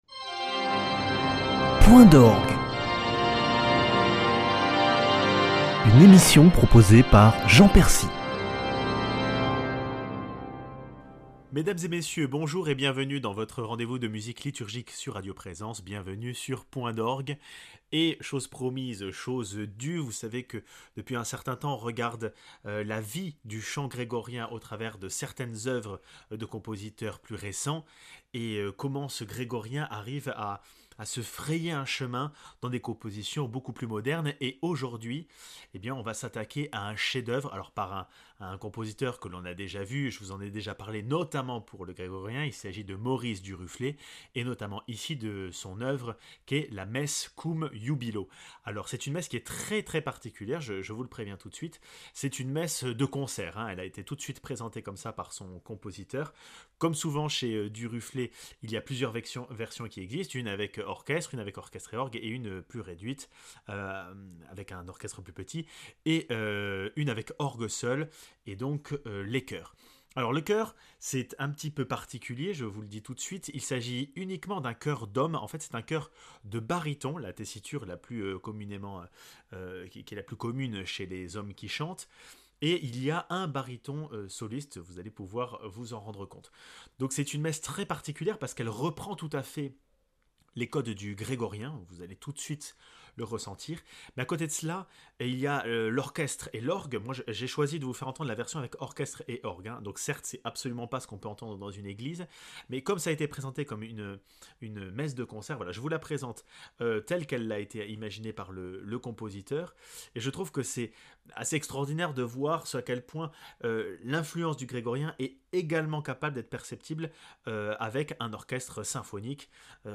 Chef d'œuvre du compositeur Maurice Duruflé, la messe Cum Jubilo pour chœur d'hommes fait partie des grandes messes du XXème siècle